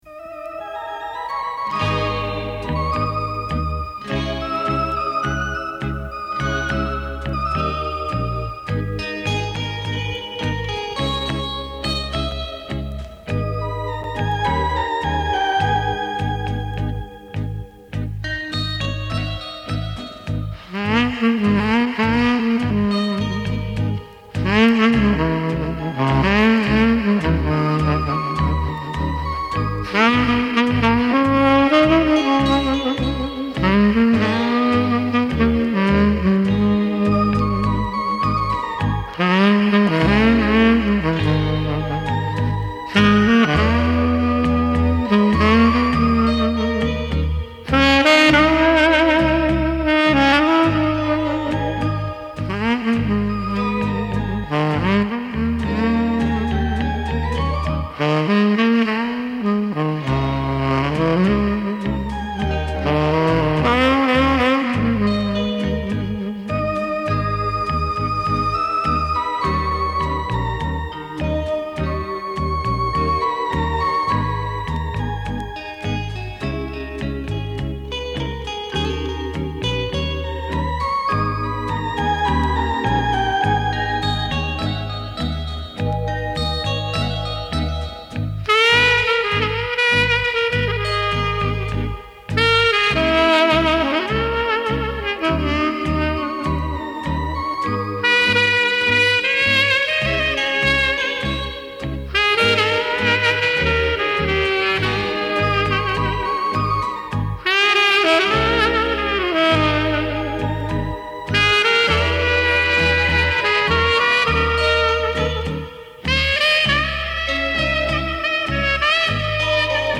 очень красивый инструментал